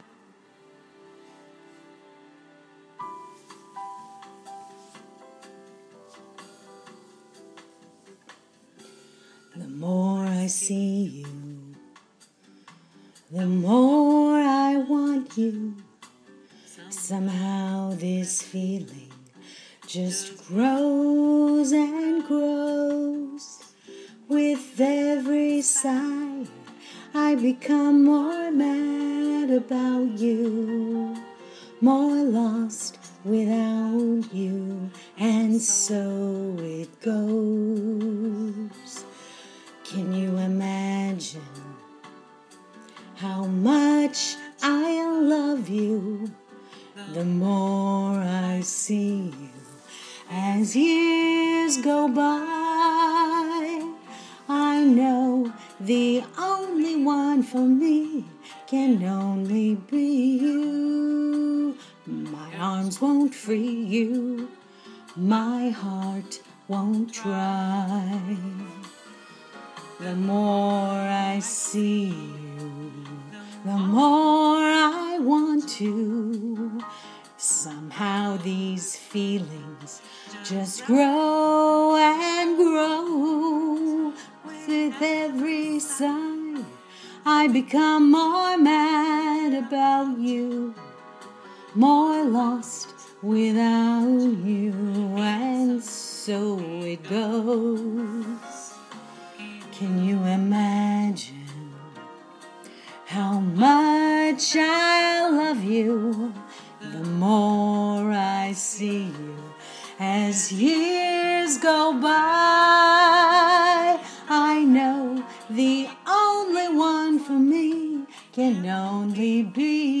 Recorded just now. One Take. No trimming or tuning.